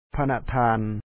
Pronunciation Notes 20
phánathāan Most Honorable